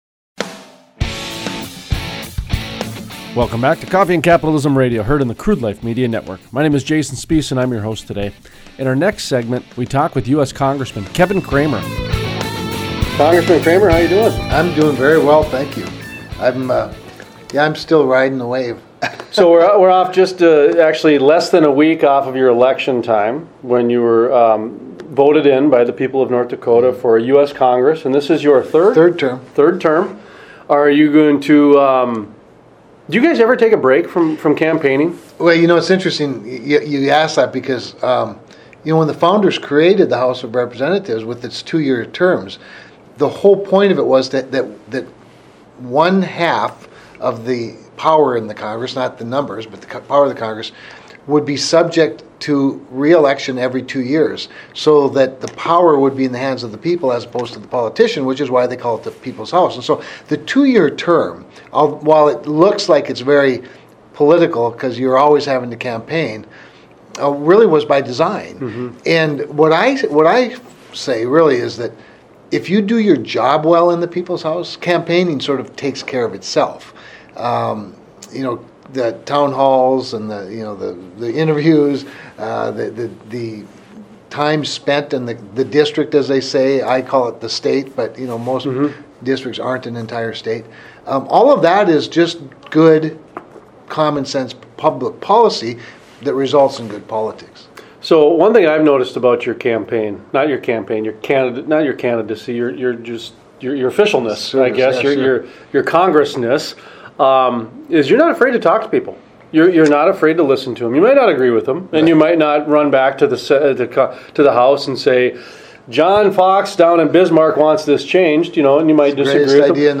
Interviews: U.S. Congressman Kevin Cramer Talks about what it is like campaigning for a federal position and how participating in town hall meetings are a big part of his strategy.